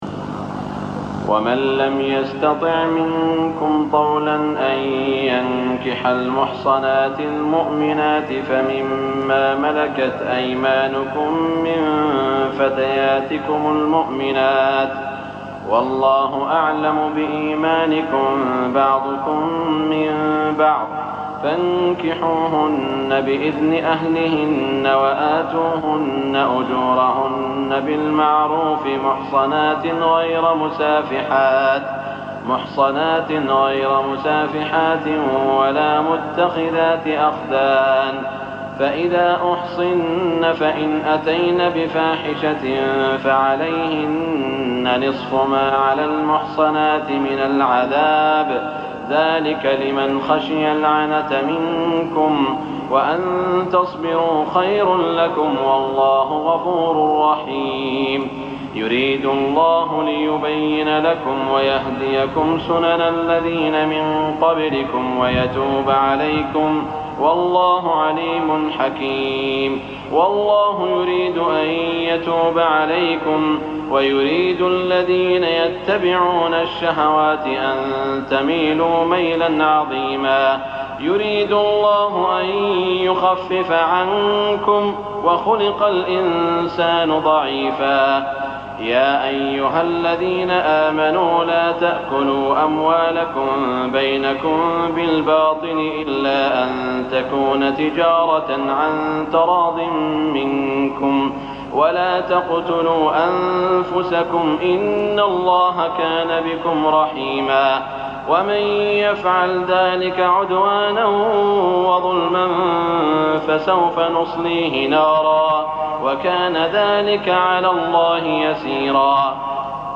تهجد ليلة 25 رمضان 1419هـ من سورة النساء (25-99) Tahajjud 25 st night Ramadan 1419H from Surah An-Nisaa > تراويح الحرم المكي عام 1419 🕋 > التراويح - تلاوات الحرمين